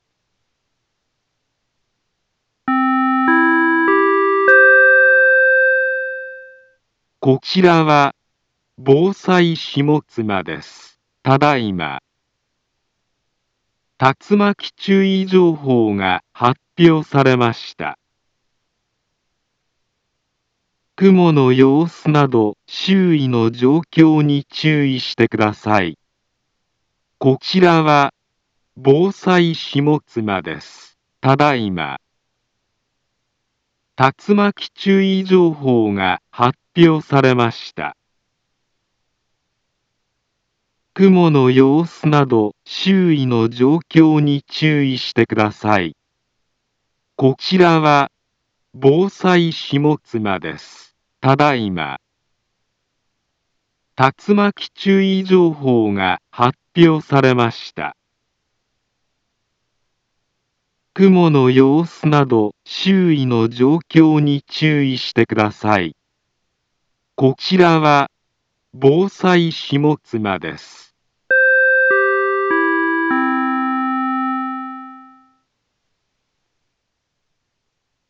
Back Home Ｊアラート情報 音声放送 再生 災害情報 カテゴリ：J-ALERT 登録日時：2025-09-03 16:48:25 インフォメーション：茨城県北部、南部は、竜巻などの激しい突風が発生しやすい気象状況になっています。